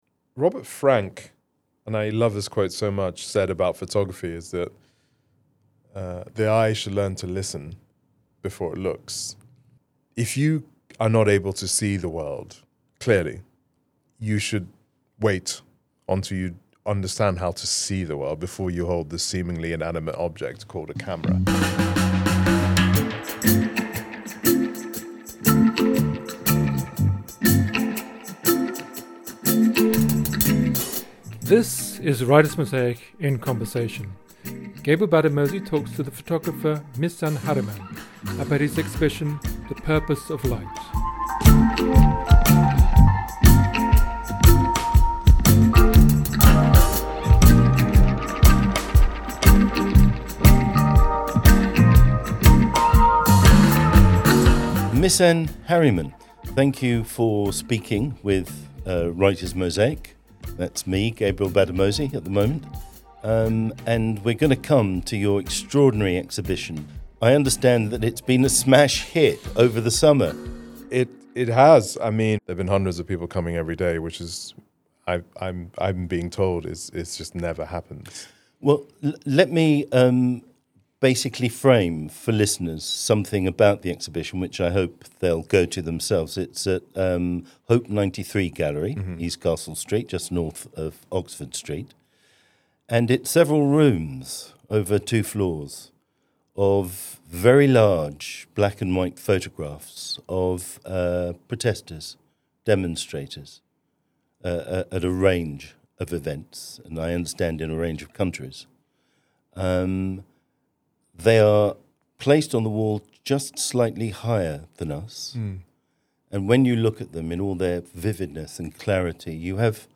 Misan Harriman talks to Gabriel Gbadamosi about the impact of his photographic exhibition, The Purpose of Light, as a 'testament to solidarity'.
In conversation